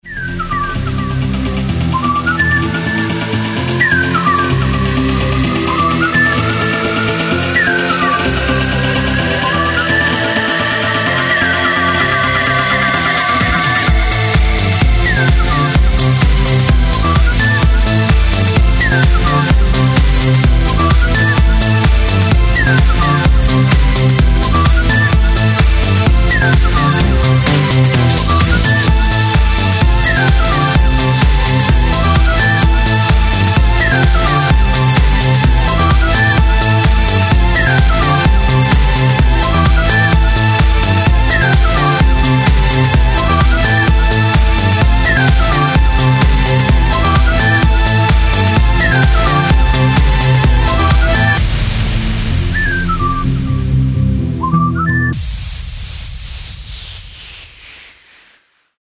• Качество: 128, Stereo
диджей из Швеции радует нас классной композицией